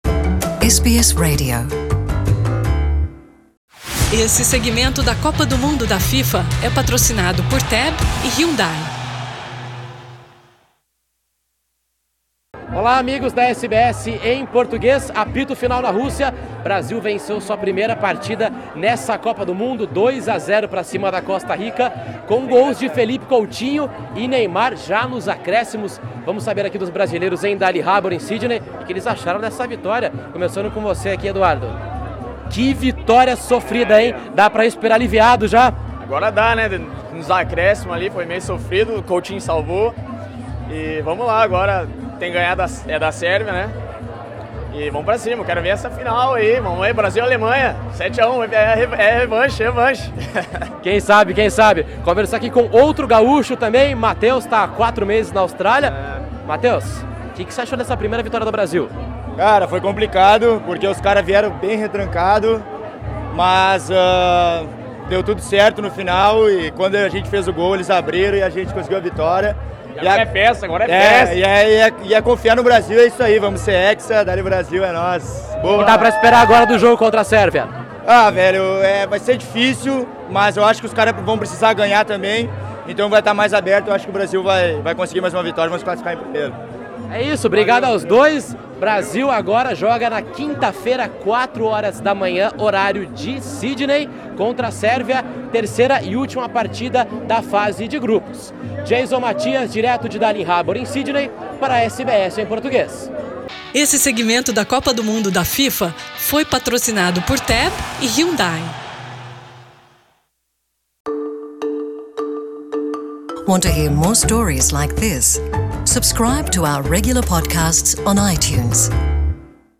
Em Sydney, mais de 500 pessoas acompanharam essa dramática vitória no Darling Harbor, que veio acompanhada de muita bebida, comida e música brasileira.